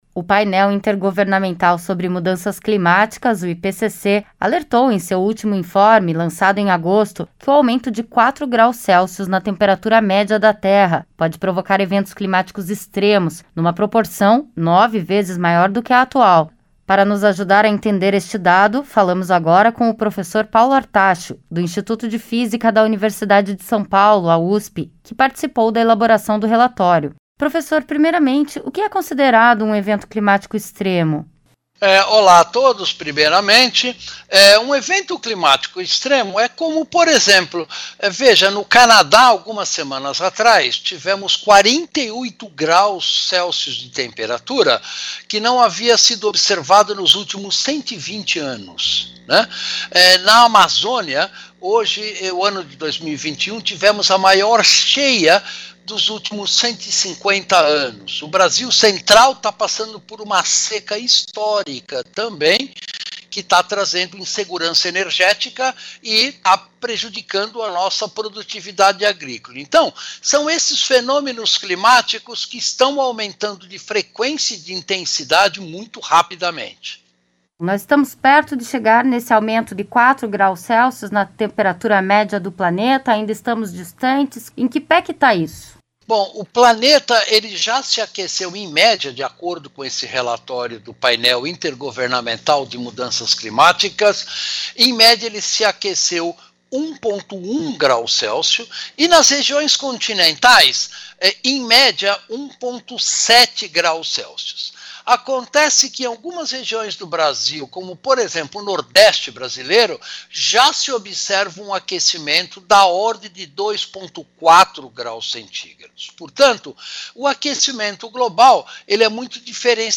entrevistou um professor